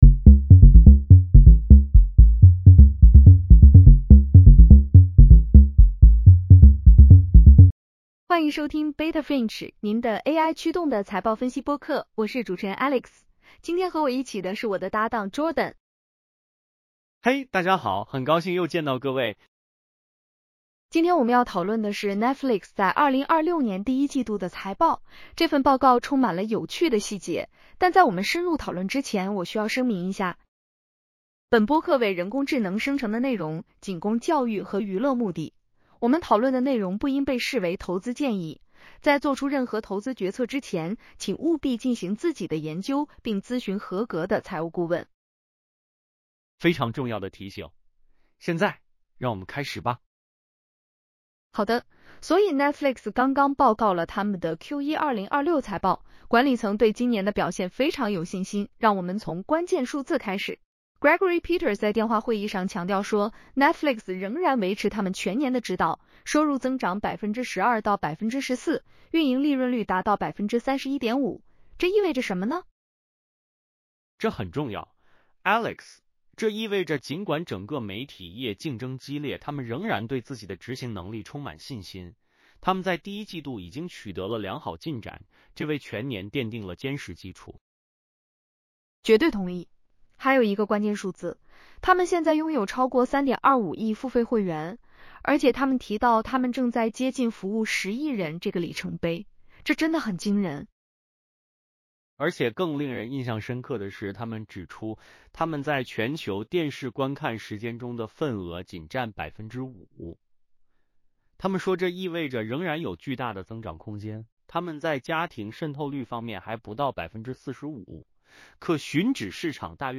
欢迎收听Beta Finch，您的AI驱动的财报分析播客。